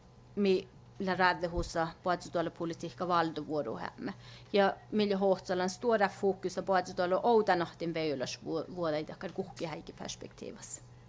Add ref female audio
ref_female.wav